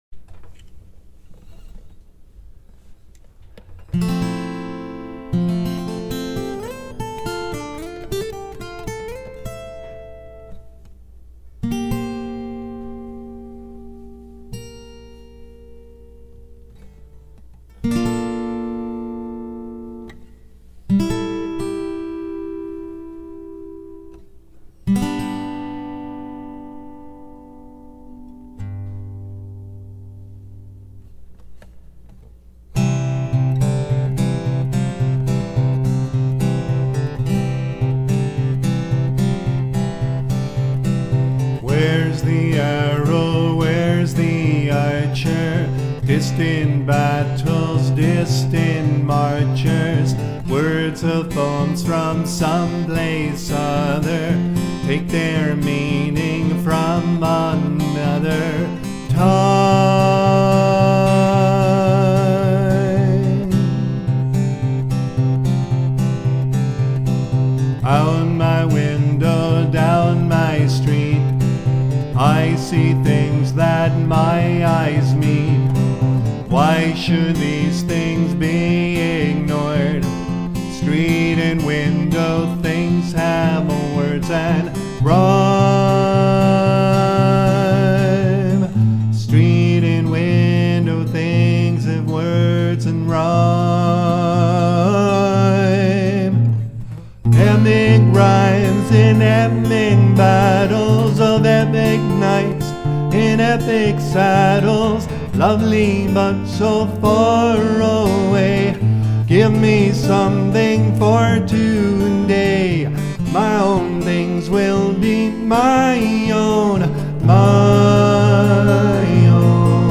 held at William Paterson University. His song "My Own Things" uses lines from William's poems as lyrics to a beautiful melody.